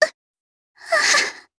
Rodina-Vox_Dead_jp.wav